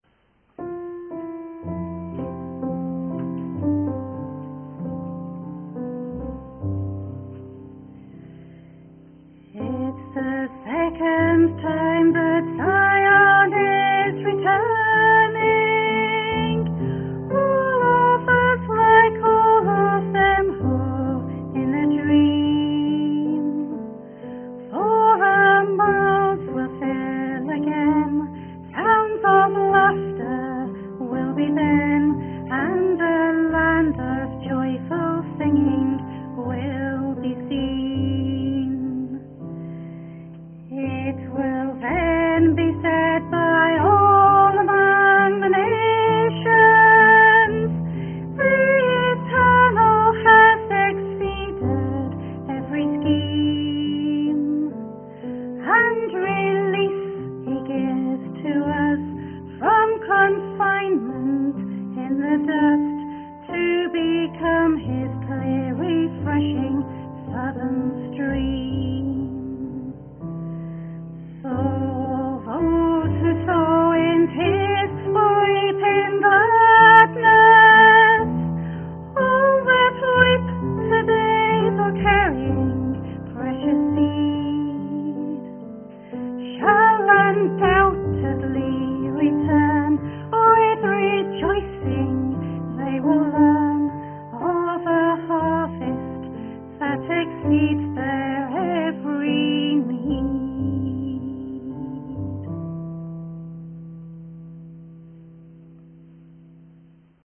Offering Music
sung Wales UK 14 Oct 2025